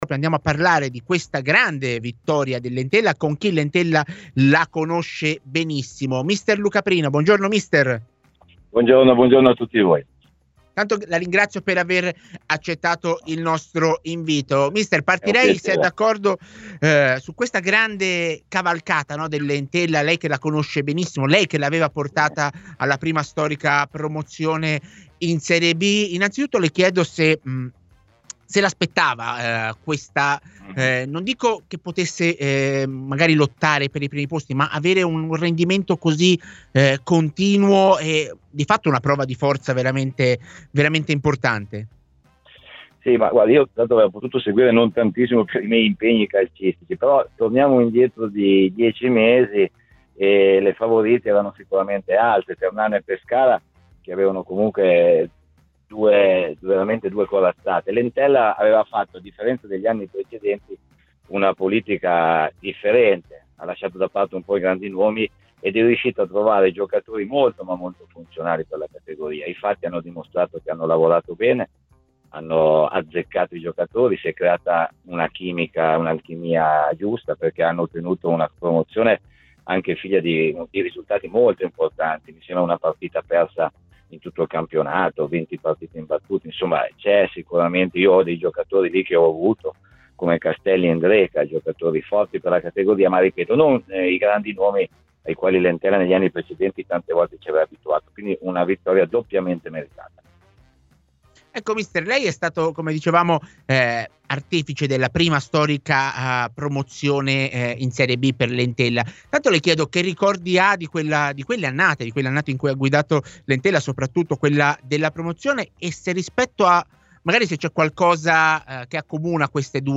Ascolta l'audio Intervista ai microfoni di TMW Radio